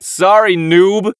hotshot_kill_04.wav